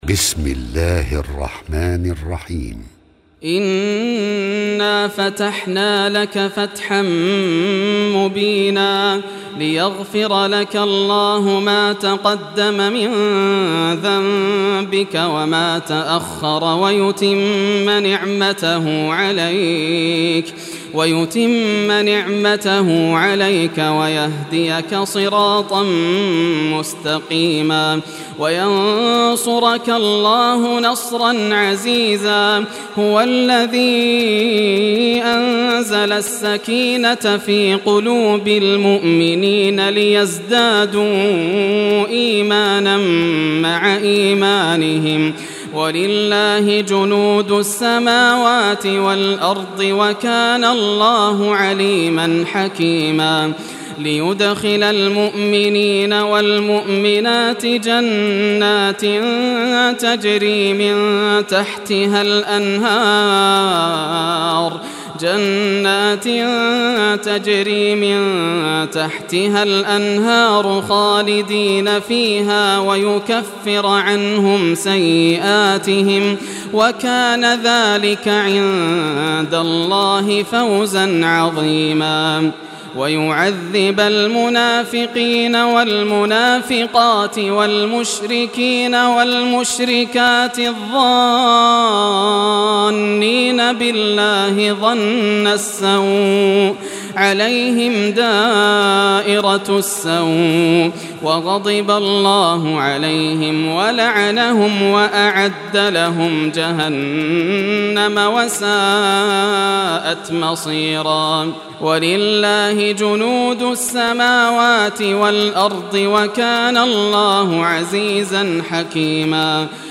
Surah Al-Fath Recitation by Yasser al Dosari
Surah Al-Fath, listen or play online mp3 tilawat / recitation in Arabic in the beautiful voice of Sheikh Yasser al Dosari.